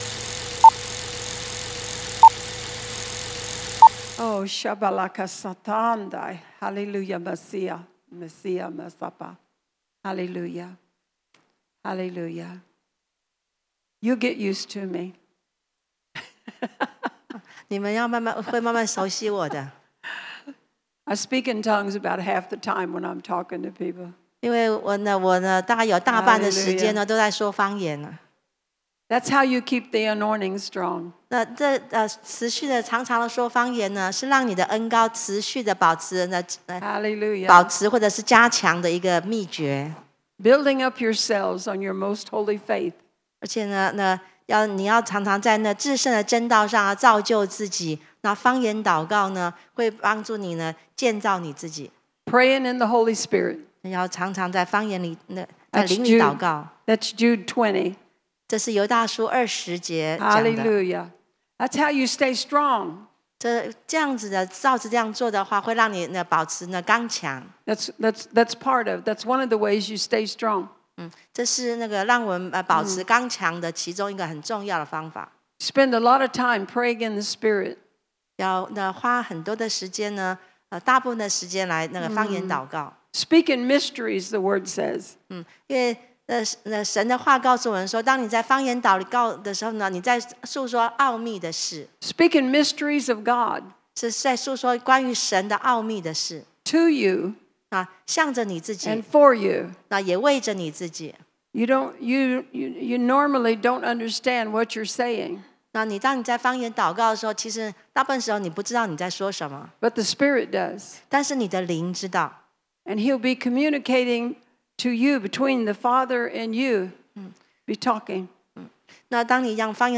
地點：台南CPE領袖學院